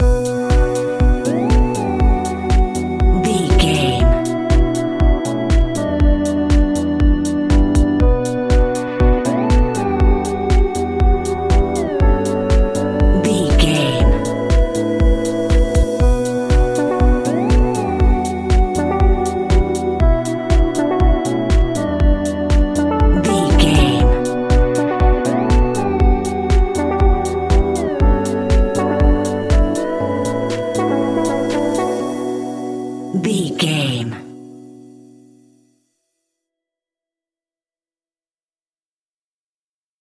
Aeolian/Minor
funky
groovy
uplifting
driving
energetic
drum machine
synthesiser
electric piano
electro house
funky house
instrumentals
synth bass